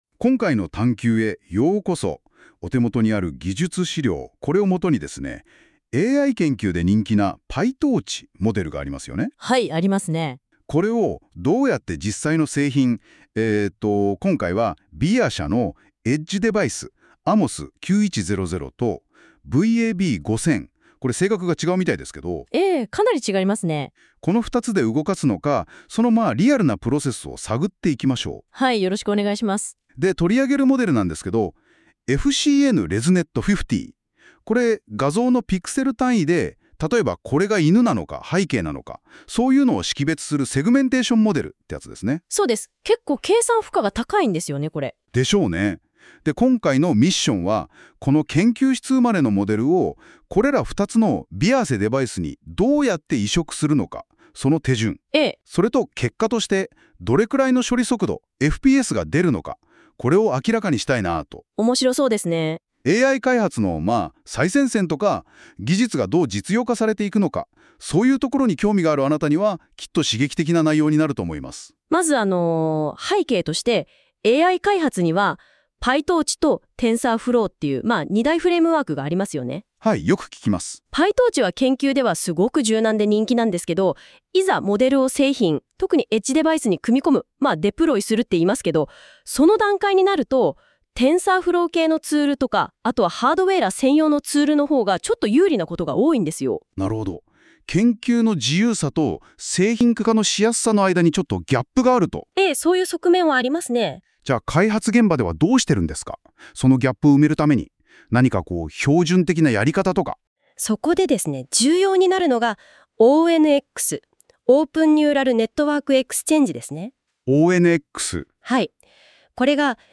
お忙しい方向けに、このページに掲載されている「PyTorchモデルをエッジデバイスで動かすPodcast」の情報を、短時間でご理解いただけるよう音声で要約・解説します。